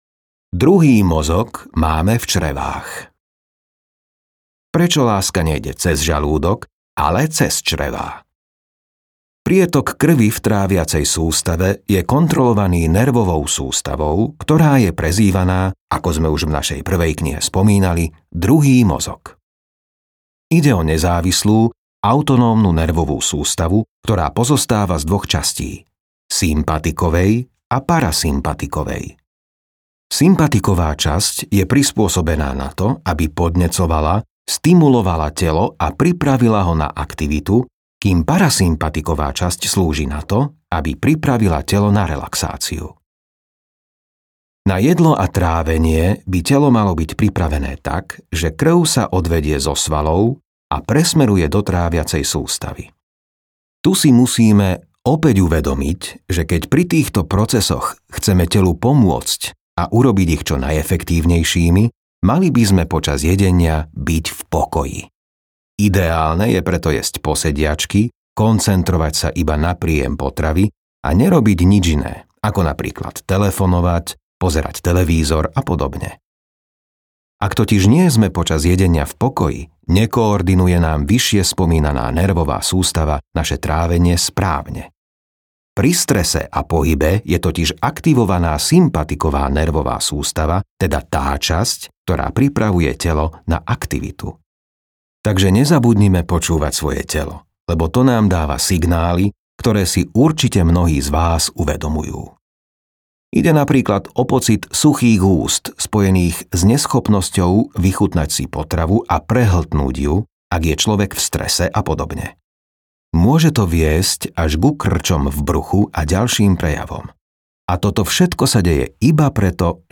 Zdravie bez liekov audiokniha
Ukázka z knihy